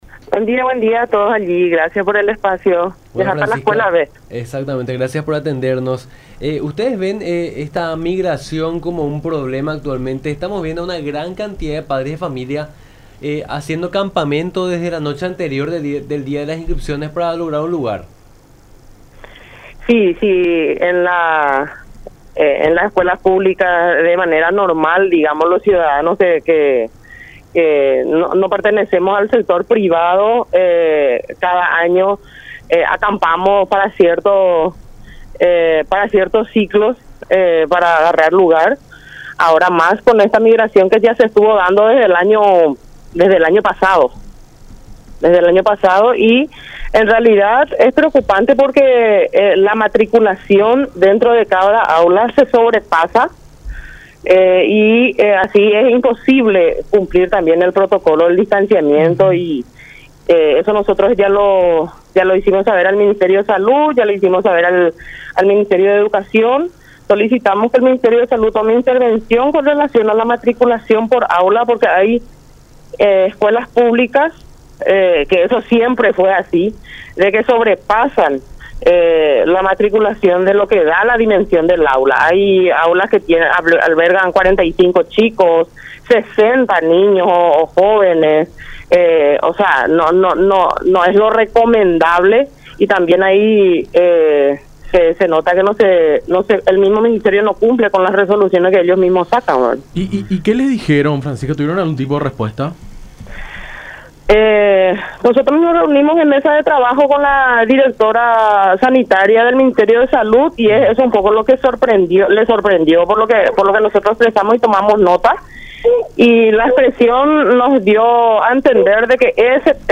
en diálogo con Nuestra Mañana por La Unión